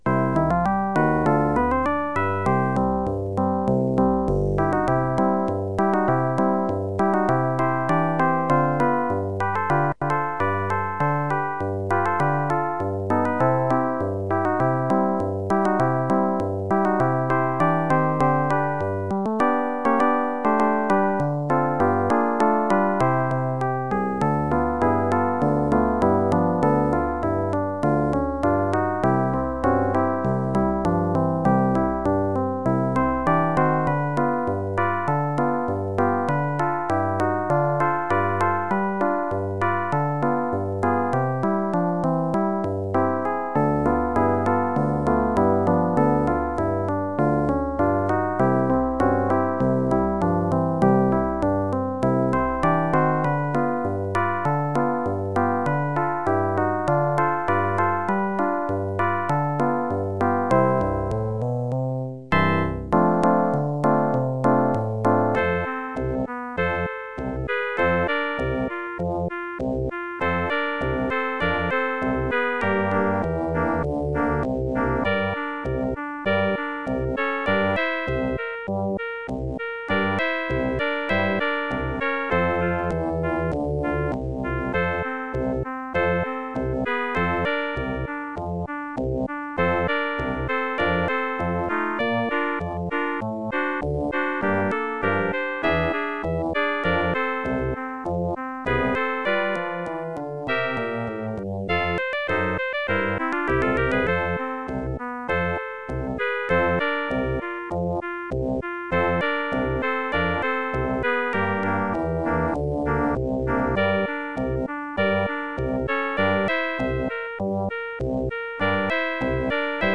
Also used for droll, sardonic, or otherwise strange moments.